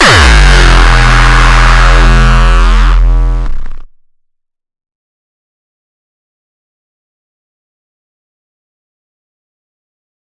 野兽（硬踢）" 野兽 A3
描述：作为我的包野兽的一部分的硬核踢。
它适用于200 BPM +
标签： 铁杆 frenchcore 扭曲 硬派音乐 唠叨的人 bassdrum 核心
声道立体声